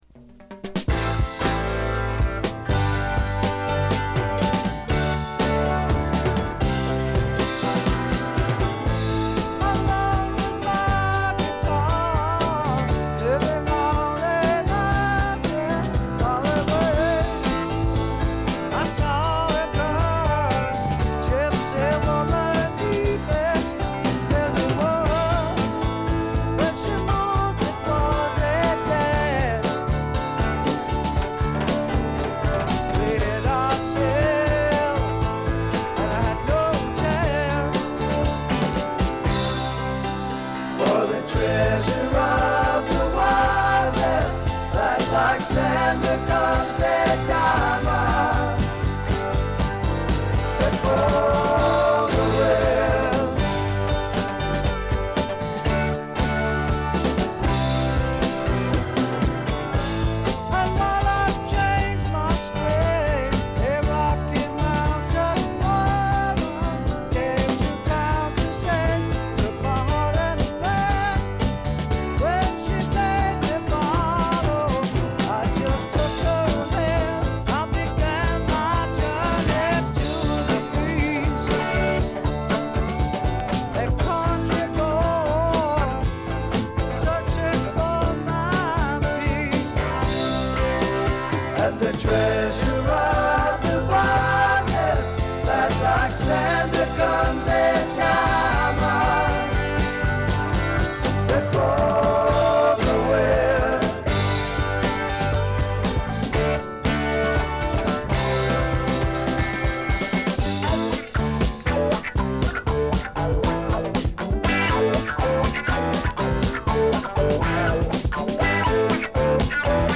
部分試聴ですがわずかなノイズ感のみ。
試聴曲は現品からの取り込み音源です。